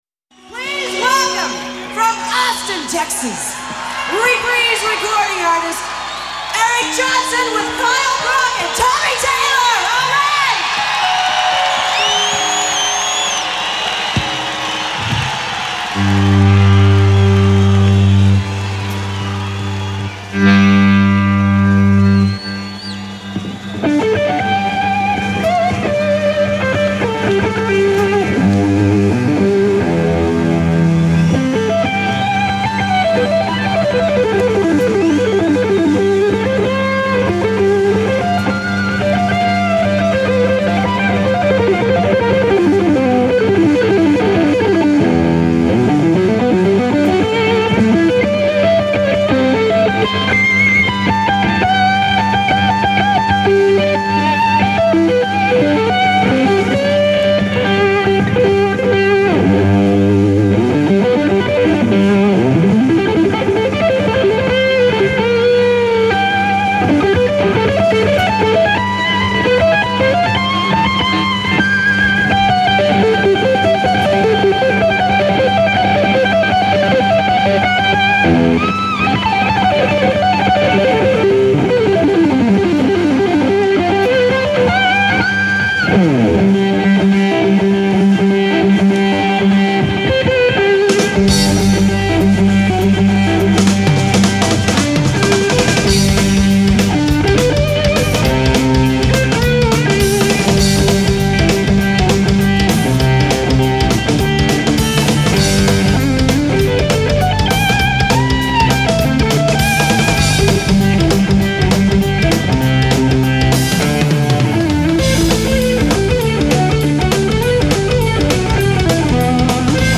guitar virtuoso
played live to a pumped up North Texas crowd of blood donors
Mixed live to two-track
at the Dallas Convention Center Arena